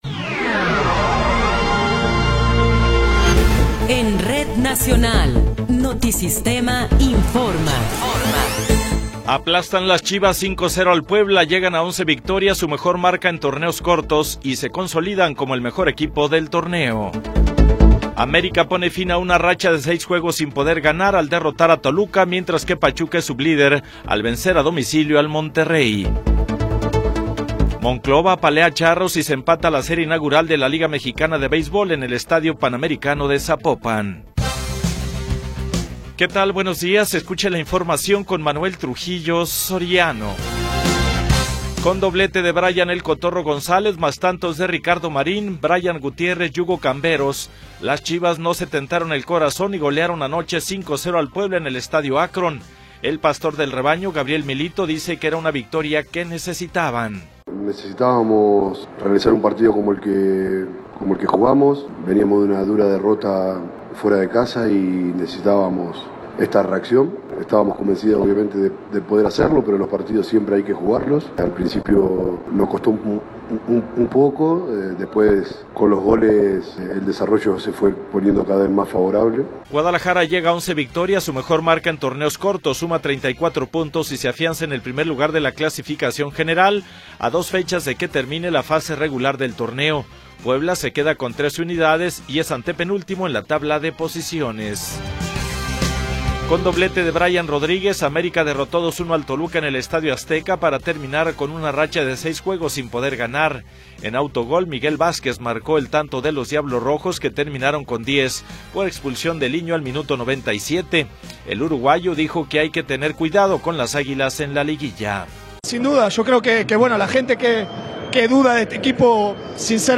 Noticiero 9 hrs. – 19 de Abril de 2026